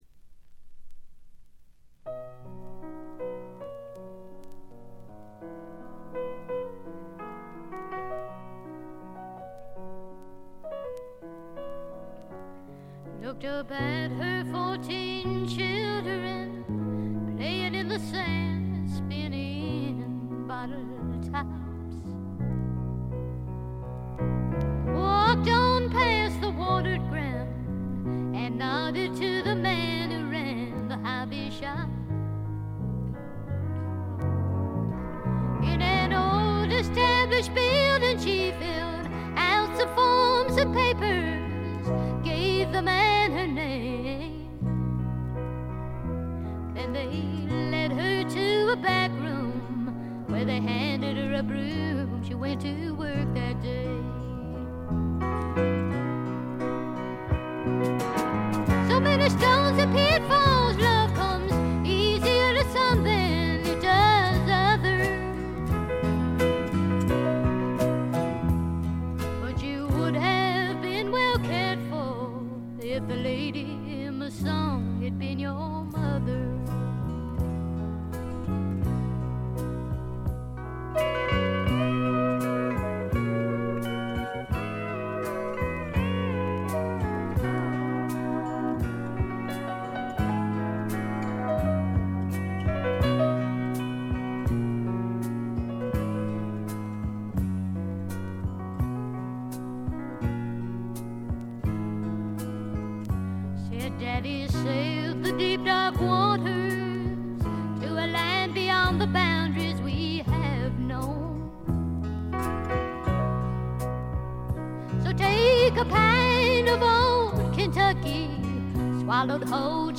全曲自作で良曲が並び、リリカルなピアノとコケティッシュなヴォーカルがとても良いです。
試聴曲は現品からの取り込み音源です。